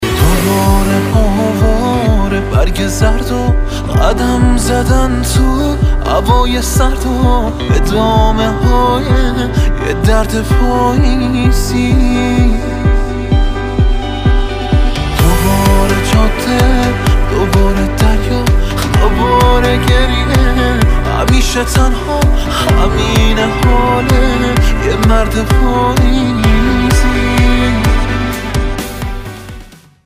زنگ موبایل احساسی و با کلام